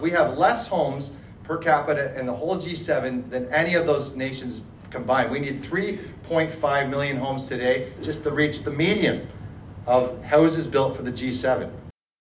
Williams was the guest speaker at Wednesday morning’s Belleville Chamber of Commerce breakfast held at Sans Souci in the Downtown District.